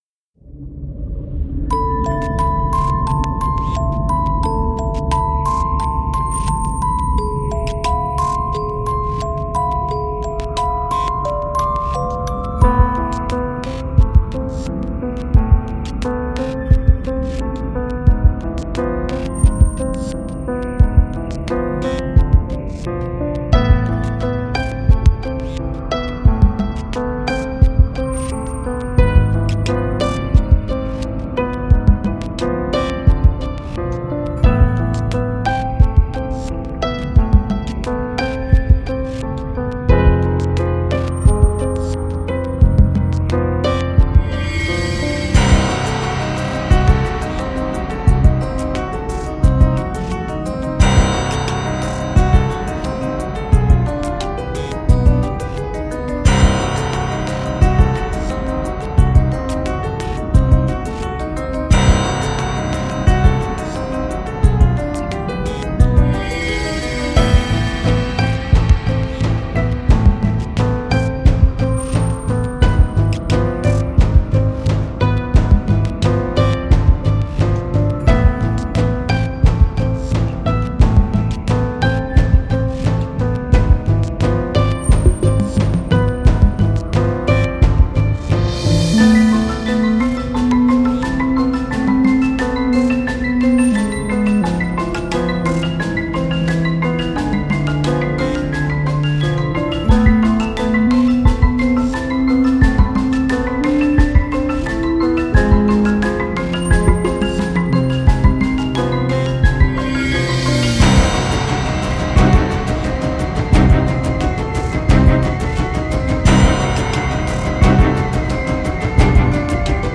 Звучание таинственной мелодии